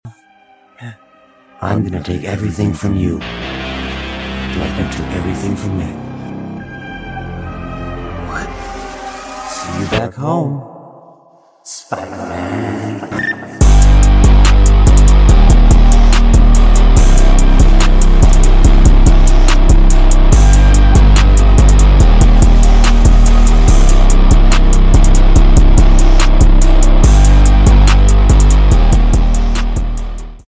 Guitar Remix